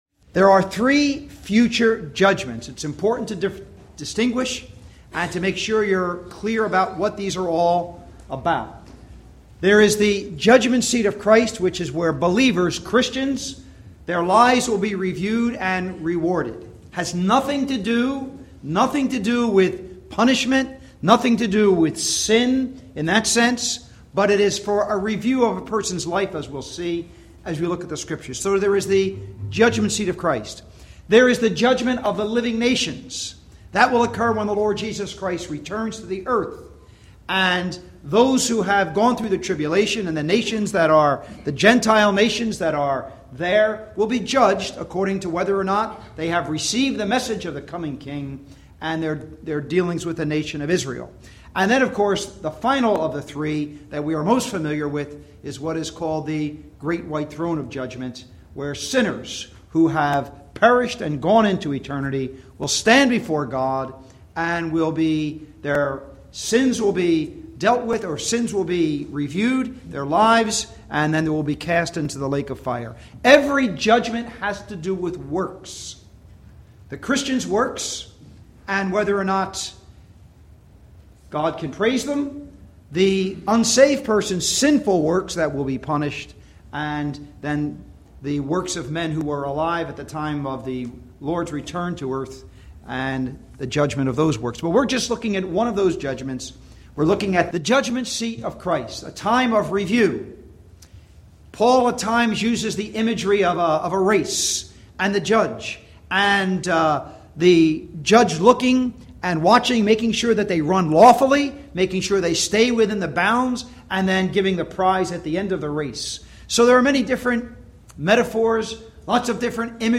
(Recorded in Barrington Gospel Hall, NJ, USA)
Doctrinal messages